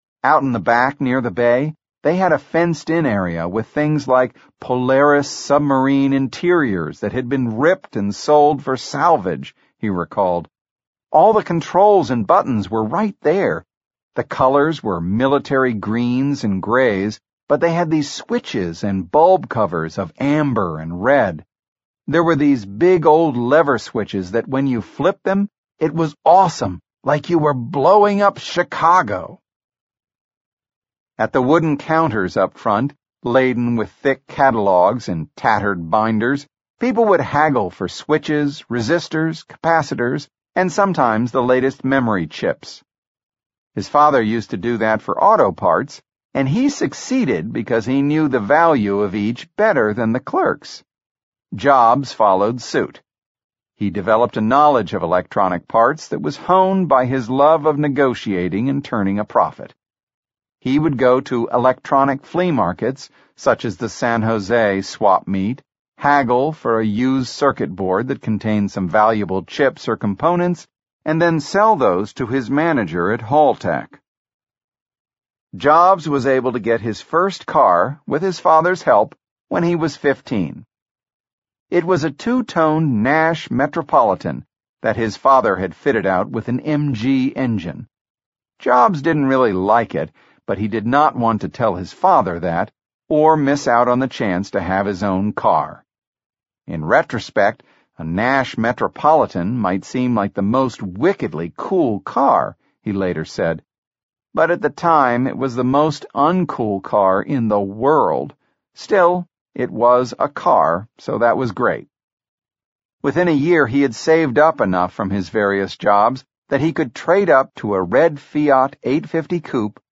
在线英语听力室乔布斯传 第18期:上学(6)的听力文件下载,《乔布斯传》双语有声读物栏目，通过英语音频MP3和中英双语字幕，来帮助英语学习者提高英语听说能力。
本栏目纯正的英语发音，以及完整的传记内容，详细描述了乔布斯的一生，是学习英语的必备材料。